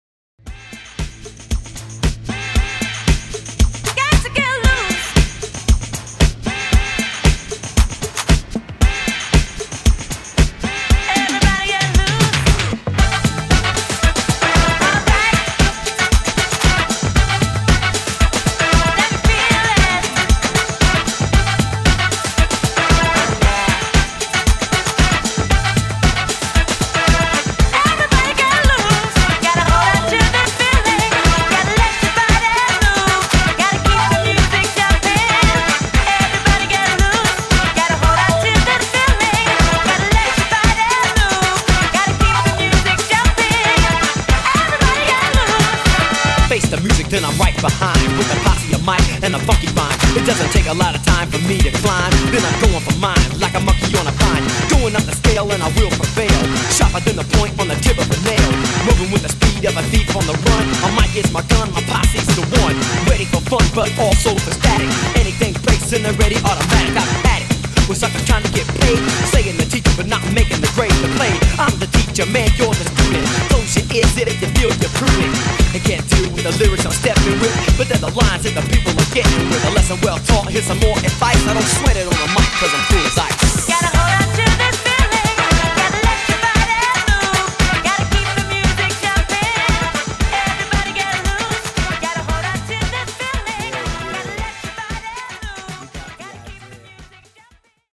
Classic Redrum)Date Added